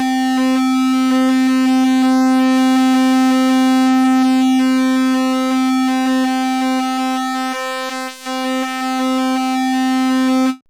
RANDOM PWM.wav